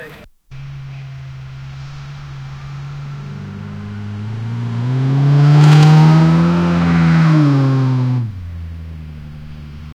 Royalty-free dirt-road sound effects
car-driving-fast-on-a-dpagb5md.wav